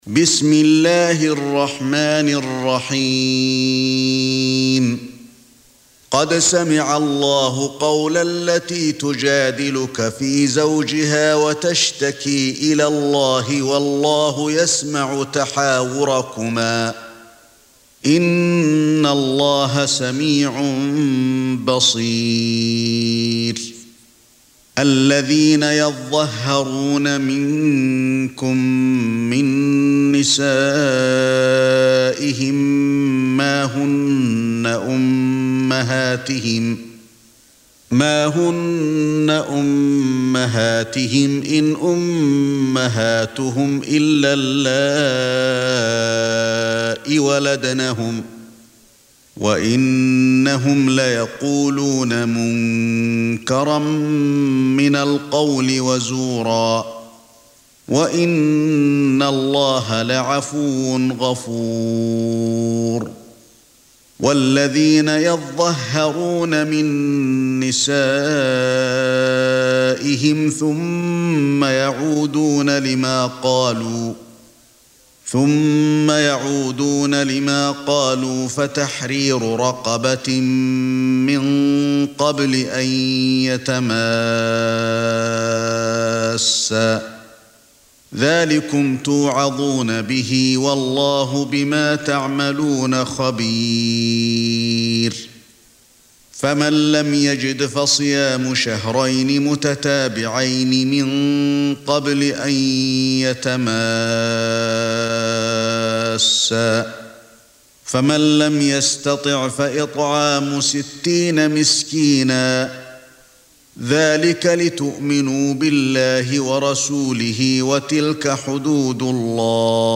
Surah Sequence تتابع السورة Download Surah حمّل السورة Reciting Murattalah Audio for 58. Surah Al-Muj�dilah سورة المجادلة N.B *Surah Includes Al-Basmalah Reciters Sequents تتابع التلاوات Reciters Repeats تكرار التلاوات